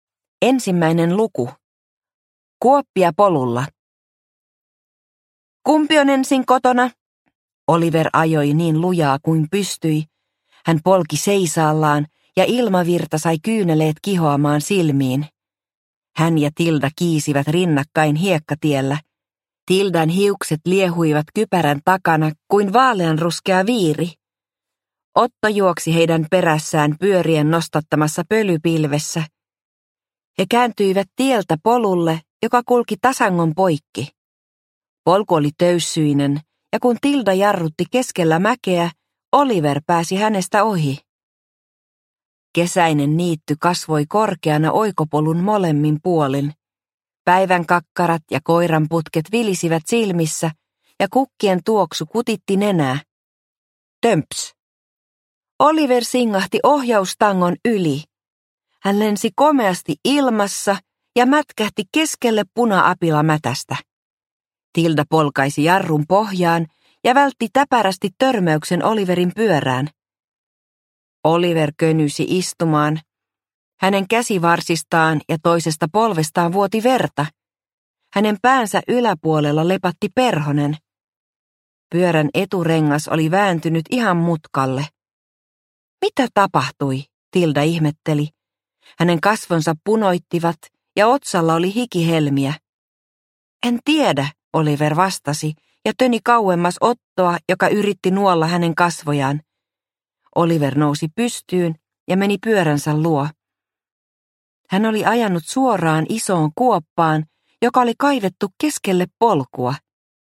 Operaatio Varjomies – Ljudbok – Laddas ner